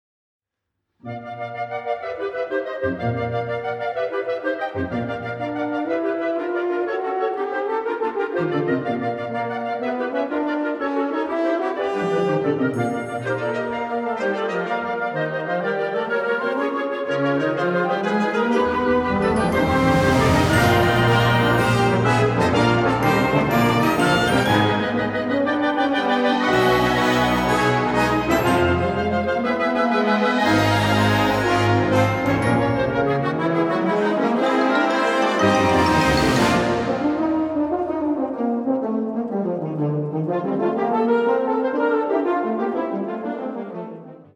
Er erzeugt eine gewisse Spannung und Dichte.
Er bringt eine heitere und offene Klangfarbe in die Musik.